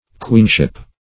Queenship \Queen"ship\, n.